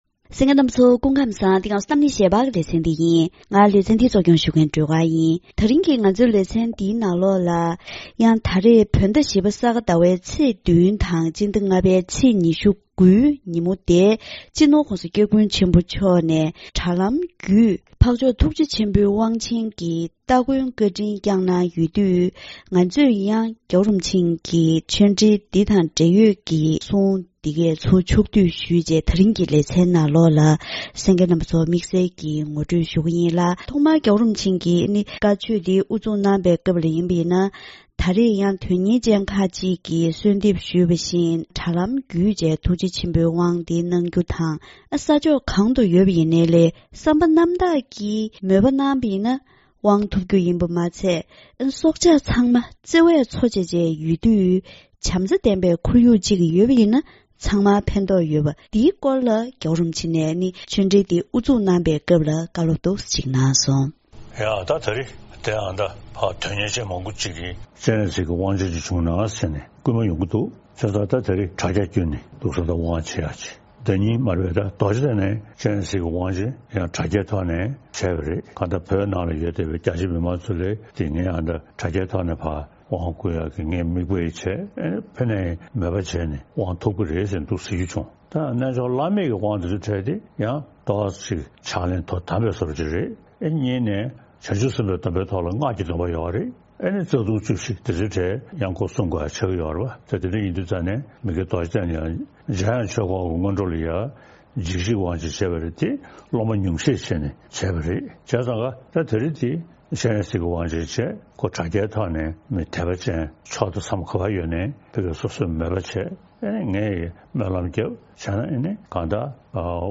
ད་རིང་གི་གཏམ་གླེང་ཞལ་པར་ལེ་ཚན་ནང་། སྤྱི་ནོར་༧གོང་ས་༧སྐྱབས་མགོན་ཆེན་པོ་མཆོག་ནས་དྲ་ལམ་བརྒྱུད་ཐུགས་རྗེ་ཆེན་པོའི་བཀའ་དབང་གི་སྟ་གོན་སྐབས་སྲོག་ཆགས་ཚང་མ་བརྩེ་བས་འཚོ་བཞིན་ཡོད་པ་དང་། བྱམས་བརྩེ་ལྡན་པའི་ཁོར་ཡུག་ཅིག་ཡོད་ན་ཚང་མར་ཕན་རྒྱུ། སྟ་གོན་ཞུས་ཏེ་སྟོང་ཉིད་ཀྱི་ལྟ་བ་དང་བྱང་ཆུབ་ཀྱི་སེམས་གང་ཐུབ་བསམ་བློ་གཏོང་དགོས་སྐོར་སོགས་ངོ་སྤྲོད་ཞུས་པ་ཞིག་གསན་རོགས་གནང་།